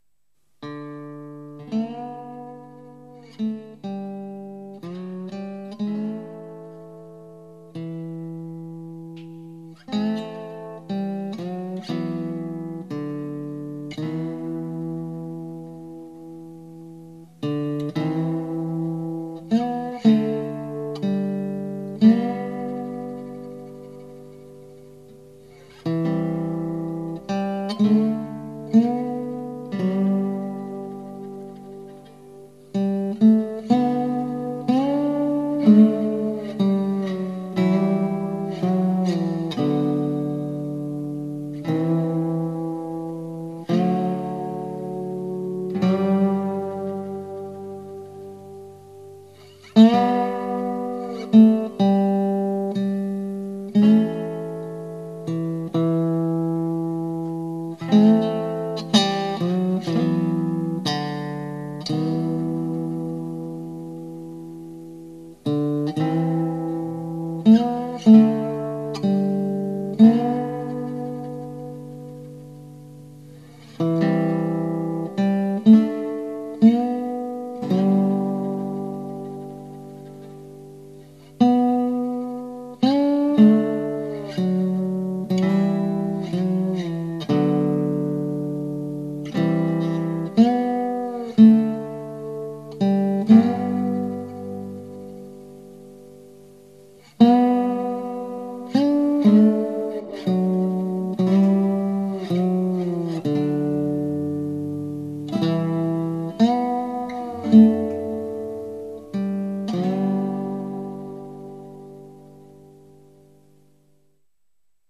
このlibraryにありますのは各芝居のために作曲・演奏・録音した曲ですが、いわゆる"カラオケ"の状態で残っているもので、これだけ聞いても何が何だかよく分からないと思われるため、このような低い階層のページにまとめて収納しています。